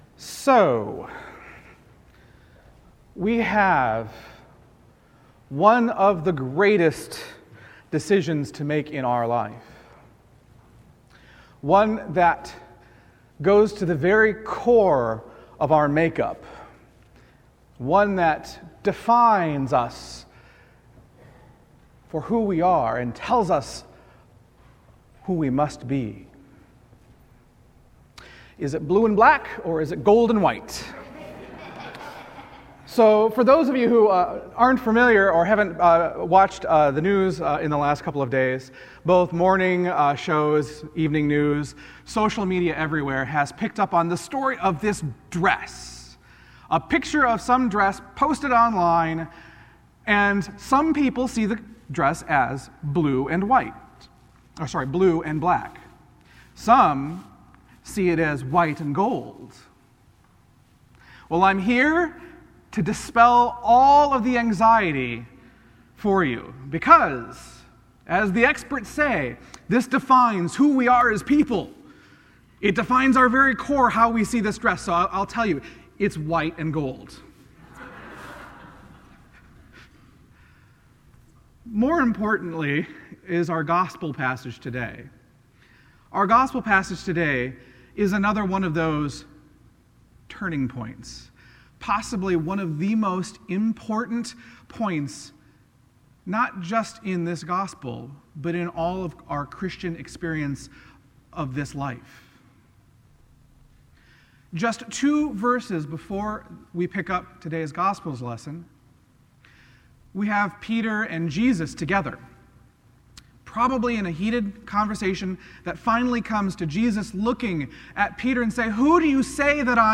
Sermons at Christ Episcopal Church, Rockville, MD
Mark 8:31-38, Jesus Foretells His Death and Resurrection Sermon